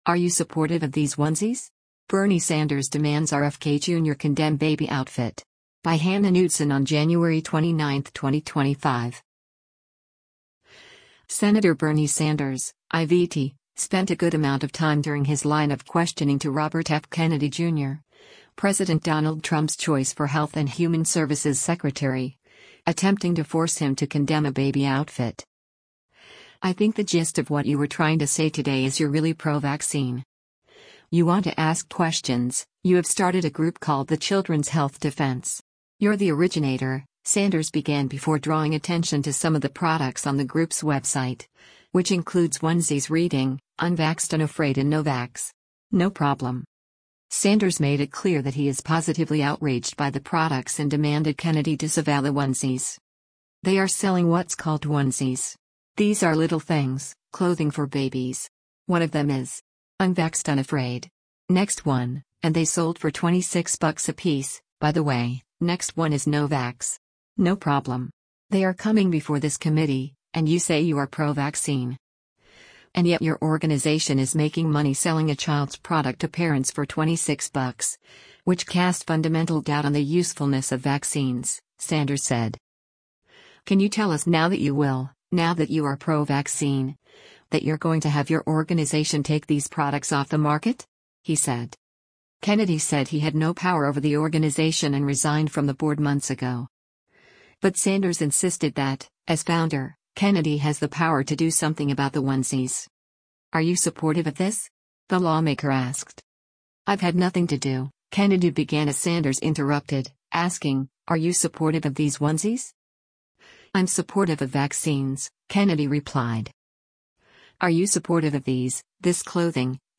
Sen. Bernie Sanders (I-VT) spent a good amount of time during his line of questioning to Robert F. Kennedy Jr. — President Donald Trump’s choice for Health and Human Services Secretary — attempting to force him to condemn a baby outfit.
“Are you supportive of these — this clothing, which is militantly anti-vaccine?” Sanders pressed, prompting a chuckle from Kennedy.
“You will not tell the organization you founded not to continue selling that product,” the senator angrily closed.